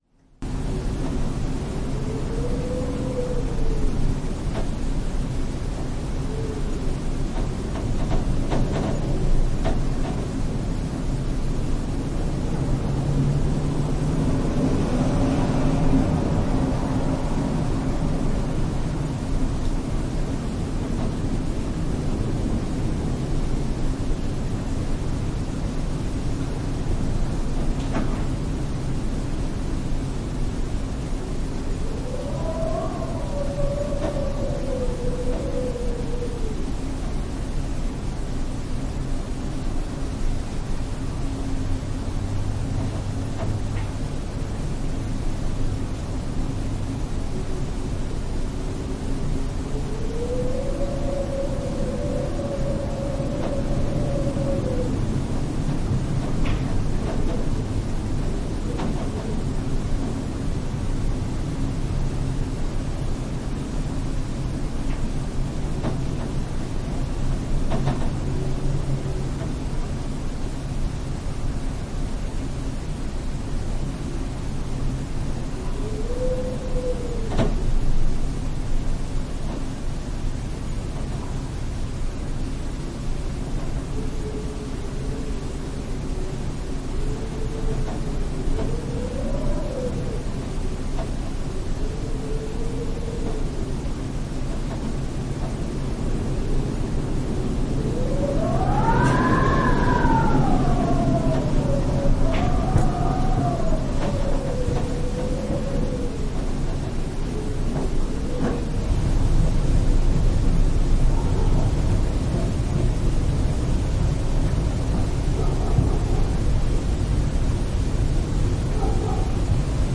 Wind_Quiet_Creaks_(1).mp3